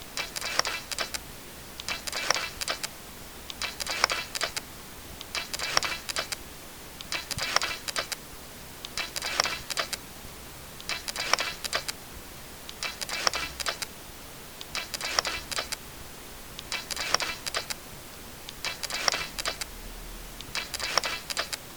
Nikon D800 kontraszt AF állításának hangja (MP3) élőkép módban.
nikon_af_contrast.mp3